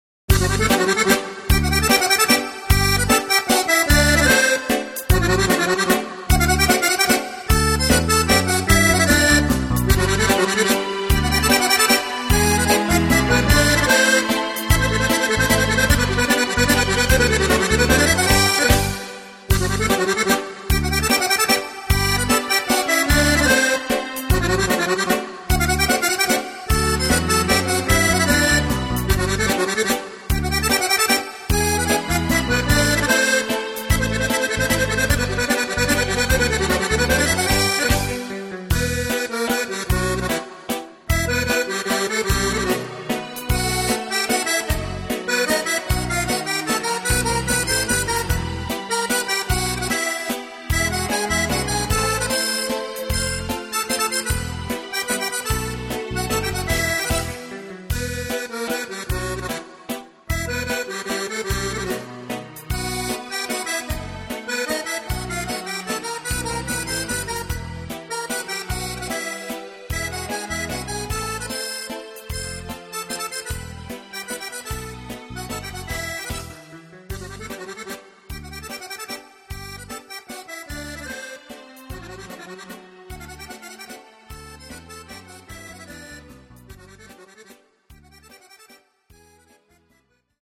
Mazurka